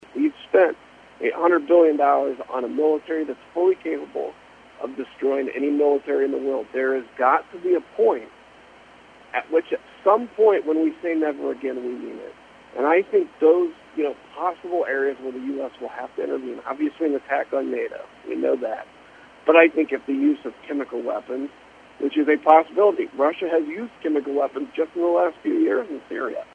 The congressman also said on WCMY’s Morning Meeting Tuesday that you still can’t trust Russian President Vladimir Putin’s version of what’s happening in the war.
Here is some of the interview on the Morning Meeting speaking about the war in Ukraine.